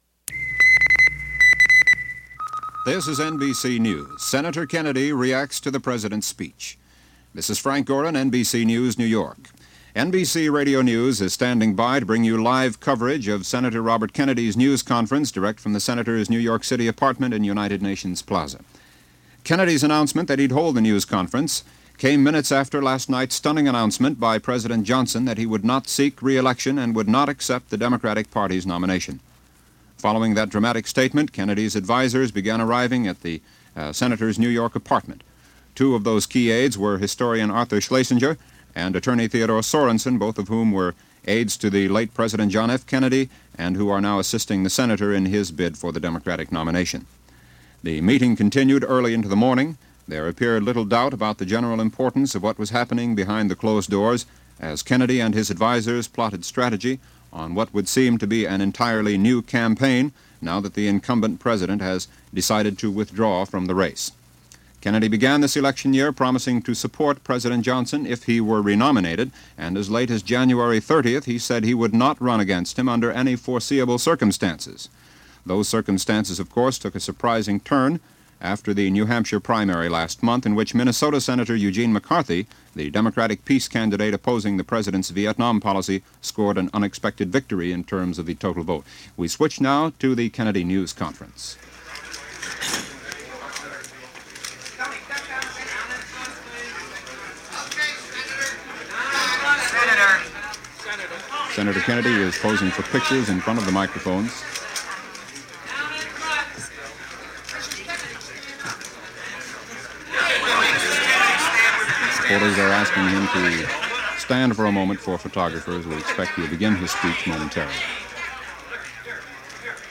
April 1, 1968 - Robert F. Kennedy Holds A Press Conference
Here is that Press Conference, as it was held on April 1, 1968 and broadcast by all the Television and Radio networks.